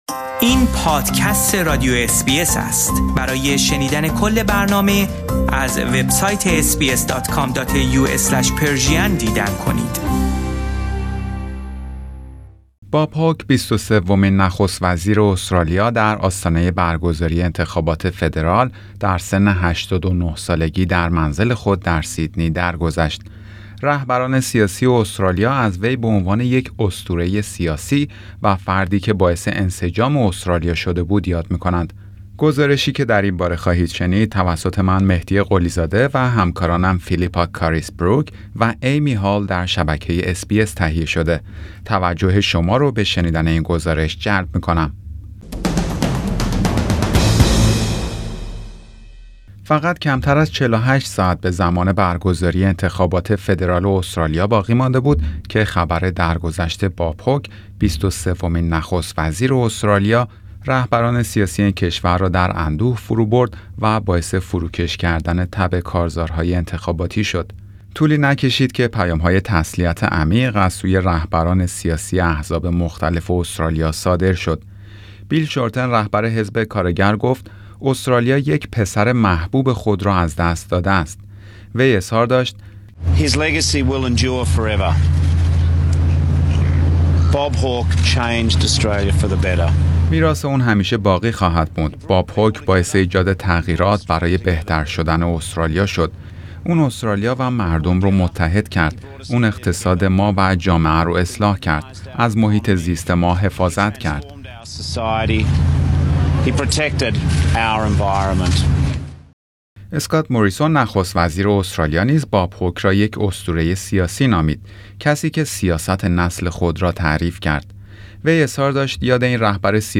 توجه شما رو به شنیدن این گزارش جلب می کنم.